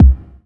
BD DM2-17.wav